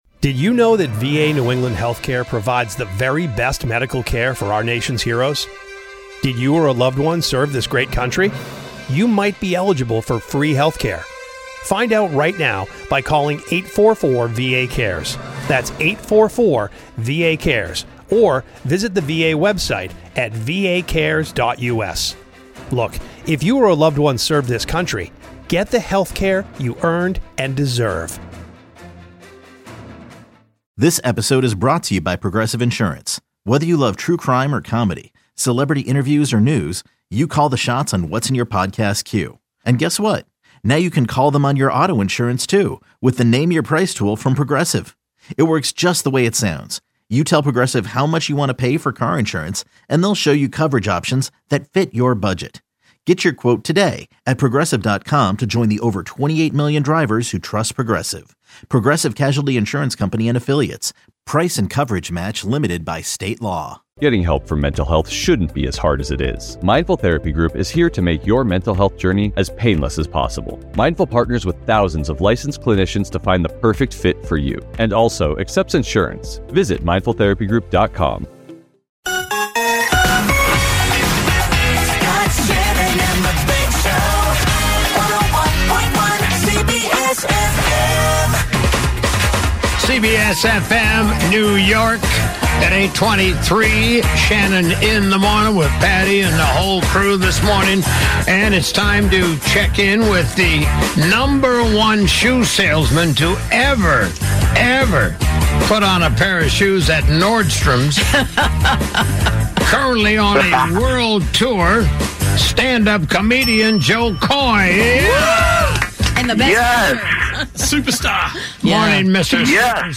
SCOTT SHANNON JO KOY INTERVIEW